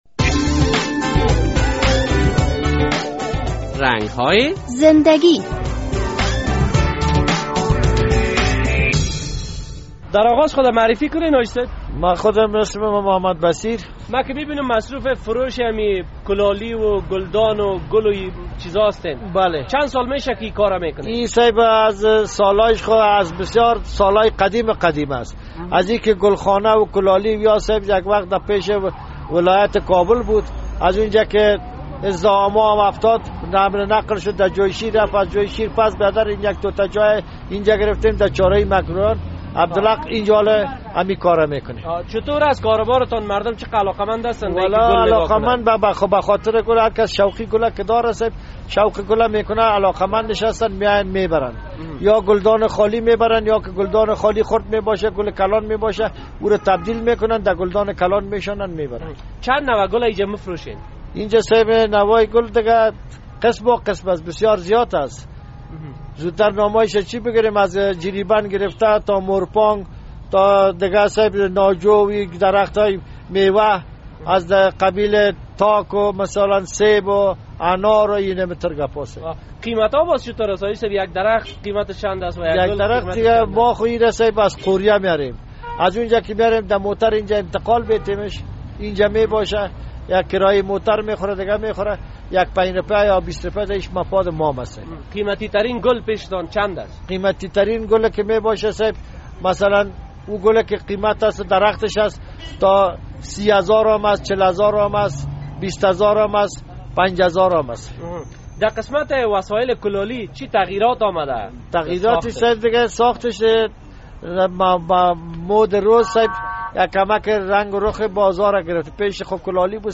در این برنامهء رنگ‌های زنده‌گی خبرنگار رادیو آزادی با یک گُلفروش مصاحبه کرده است...